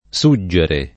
suggere [ S2JJ ere ] v.; suggo [ S2gg o ], -gi